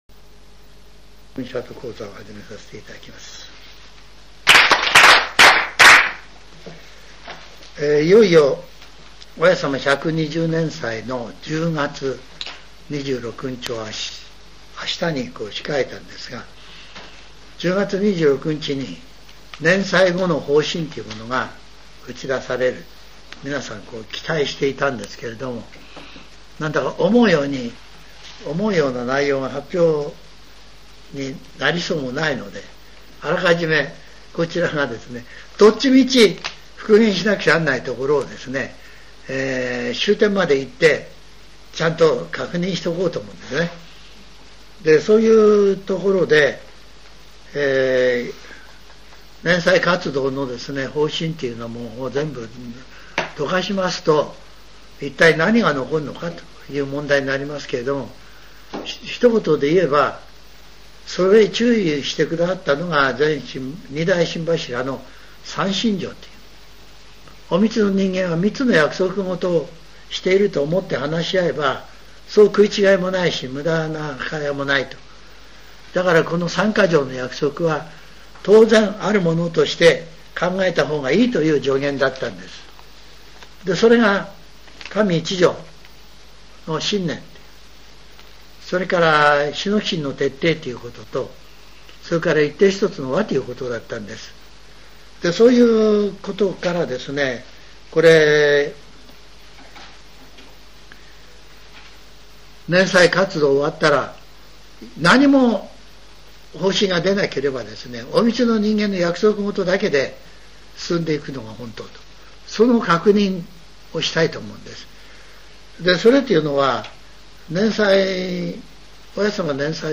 全70曲中53曲目 ジャンル: Speech